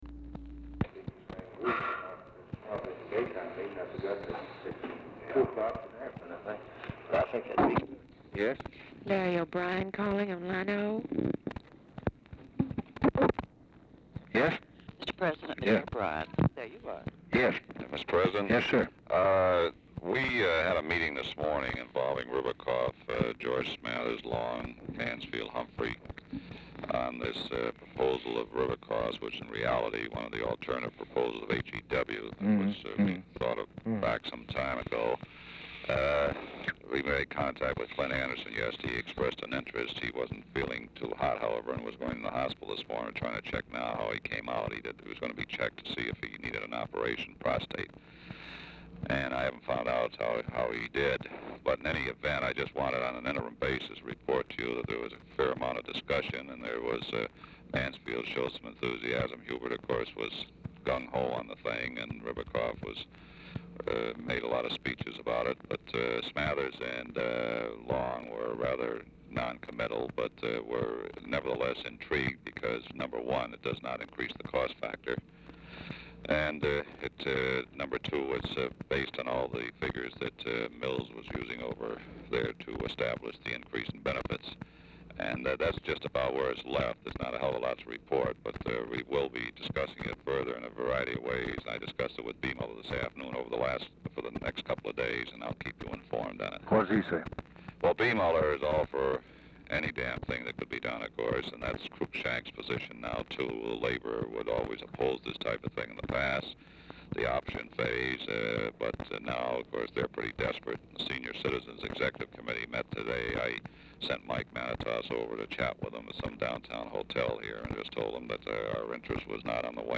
BRIEF OFFICE CONVERSATION PRECEDES CALL
Format Dictation belt
Specific Item Type Telephone conversation